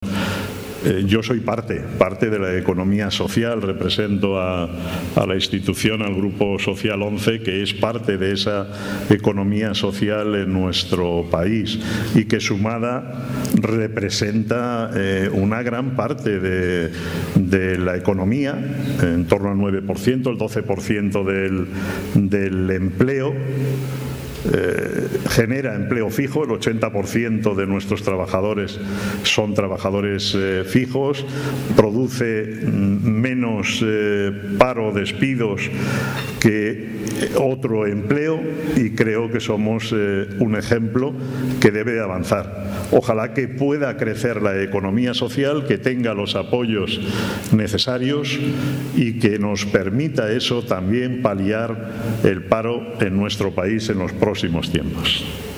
en el transcurso de un desayuno informativo organizado por Nueva Economía Forum